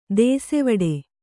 ♪ dēsevaḍe